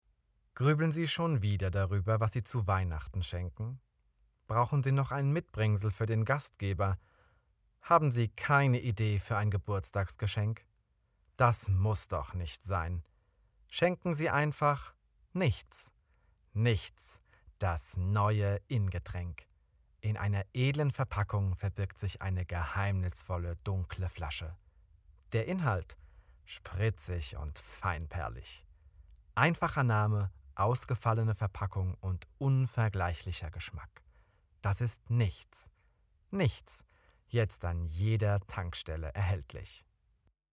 Sprecher & Moderator